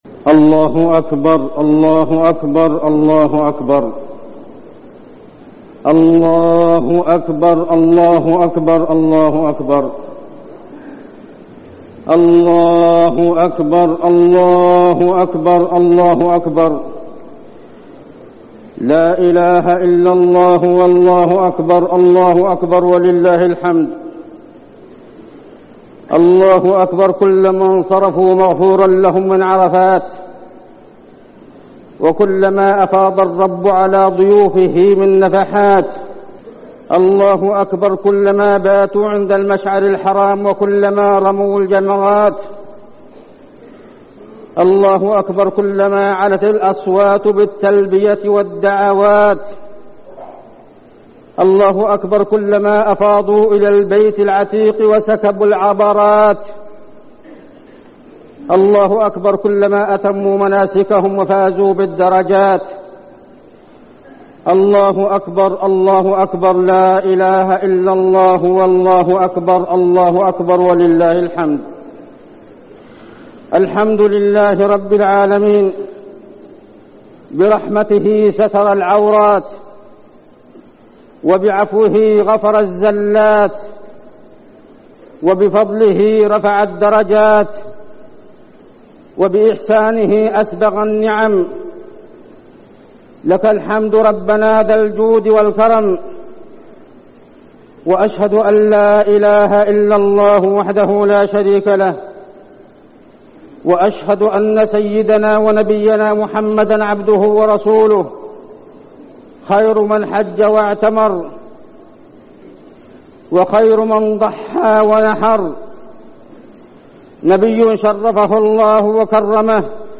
خطبة عيد الأضحى - المدينة - الشيخ عبدالله الزاحم
تاريخ النشر ١٠ ذو الحجة ١٤١٣ هـ المكان: المسجد النبوي الشيخ: عبدالله بن محمد الزاحم عبدالله بن محمد الزاحم خطبة عيد الأضحى - المدينة - الشيخ عبدالله الزاحم